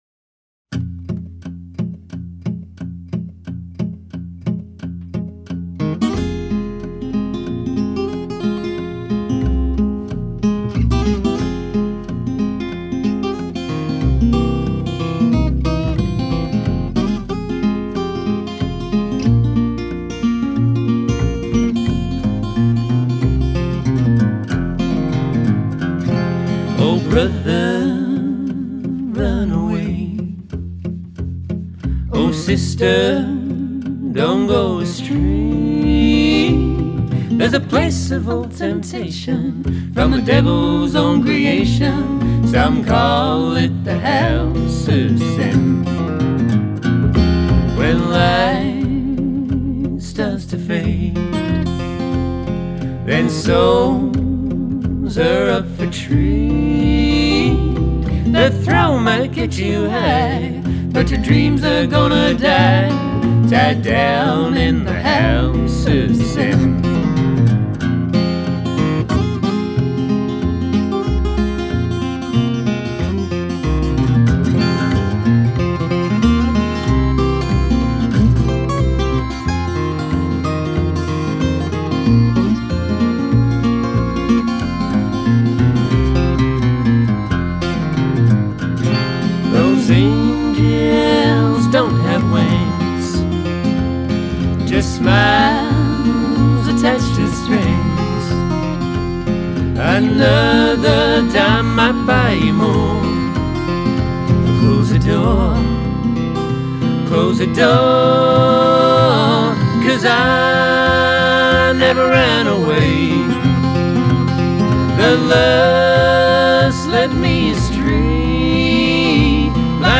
with the earthy new single and cautionary tale
With its sinuous fingerpicking and ominous note of warning
Recorded, engineered and mixed